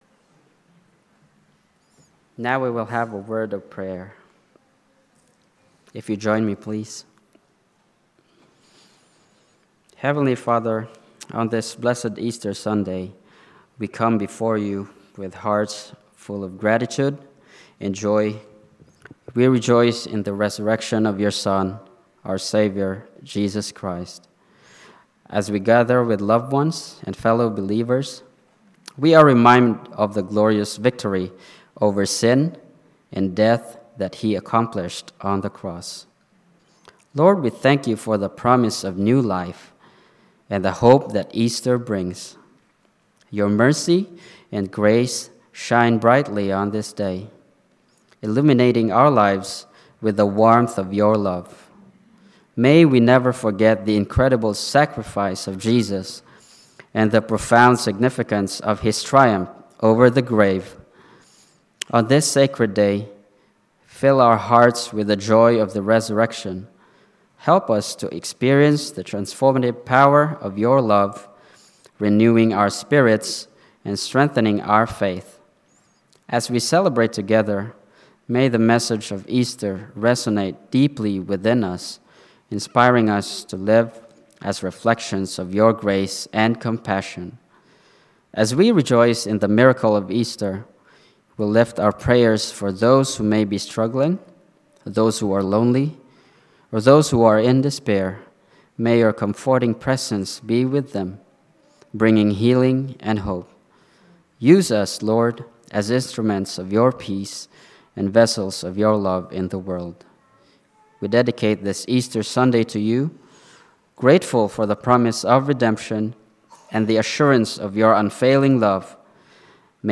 Easter Sunday Service